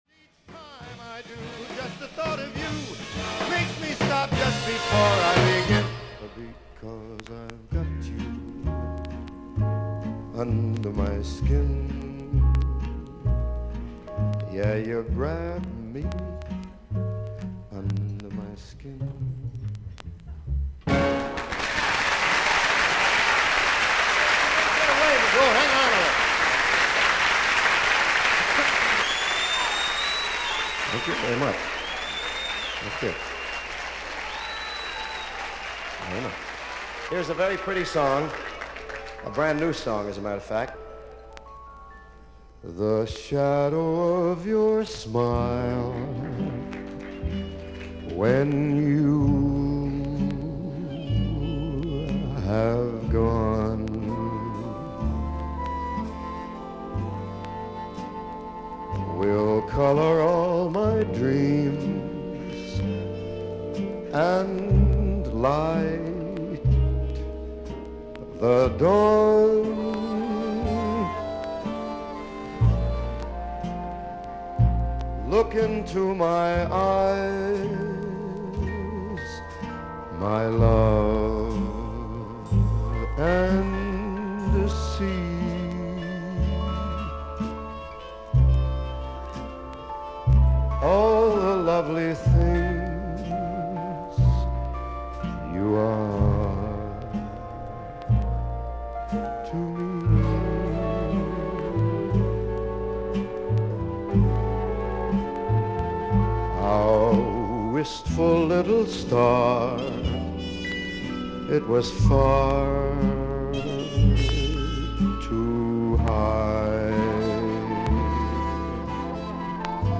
1面3曲目終盤から4曲目中盤までキズあり、少々周回ノイズあり。
少々サーフィス・ノイズあり。クリアな音です。
アメリカを代表する男性シンガー。